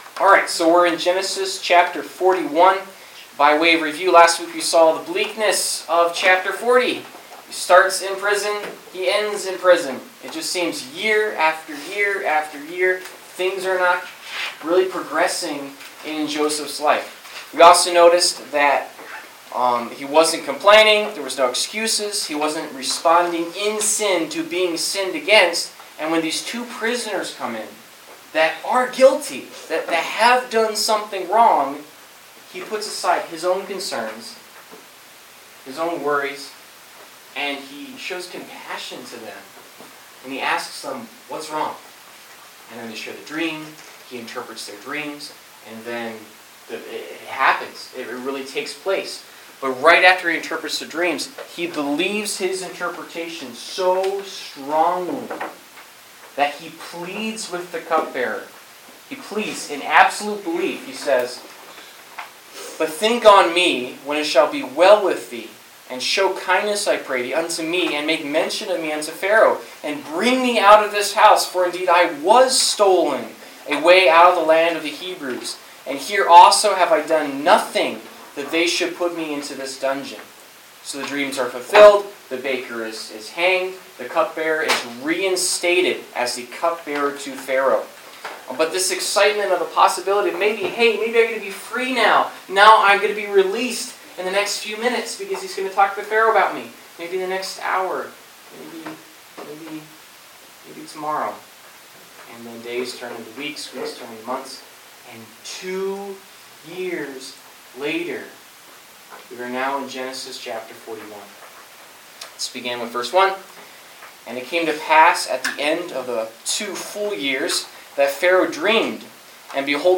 Service Type: Wednesday Night - Youth Group